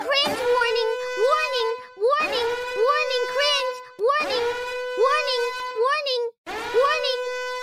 Кринж-сирена